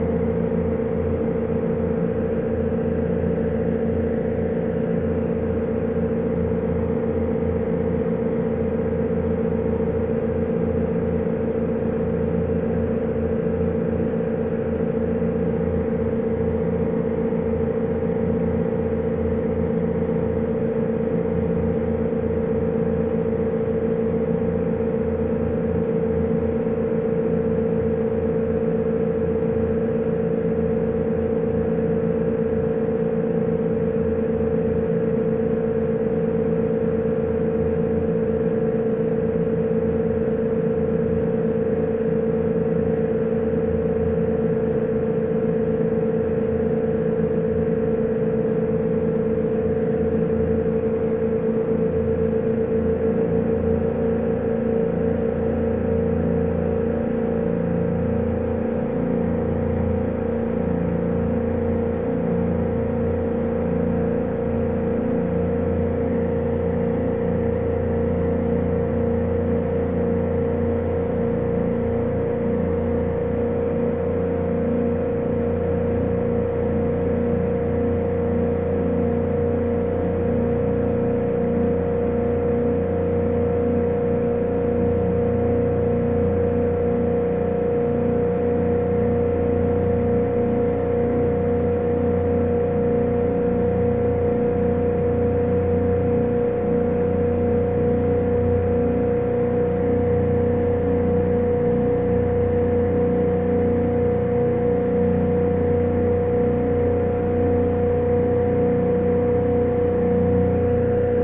随机的 "快艇舷外马达中型发动机悸动2消音
描述：快艇舷外发动机中型发动机throb2 muffled.flac
Tag: 速度 马达 外侧 发动机